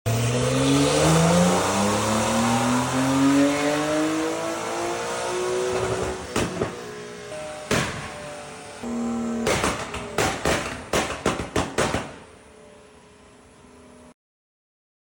Porsche 911 Carrera Base 992 sound effects free download
Porsche 911 Carrera Base 992 500Hp (stock turbos).